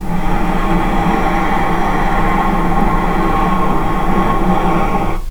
vc-C#6-pp.AIF